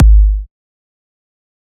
EDM Kick 8.wav